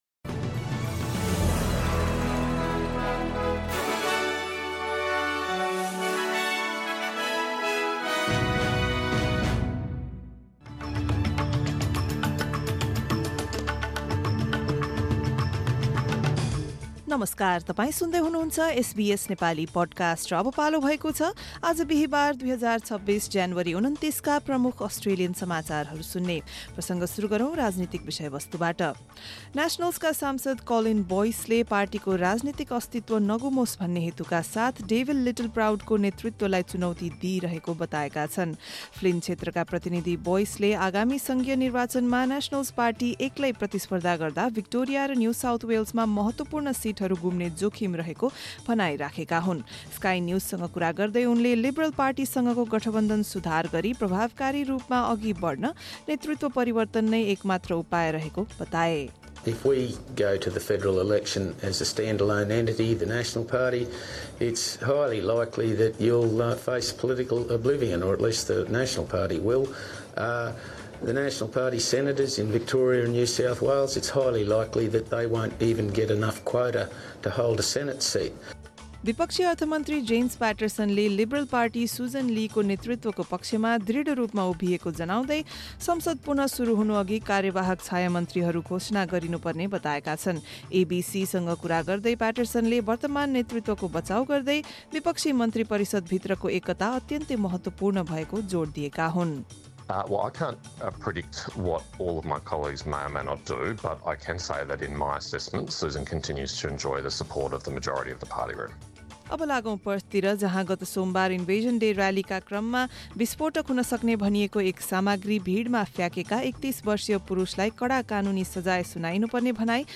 एसबीएस नेपाली प्रमुख अस्ट्रेलियन समाचार: बिहीवार, २९ ज्यानुअरी २०२६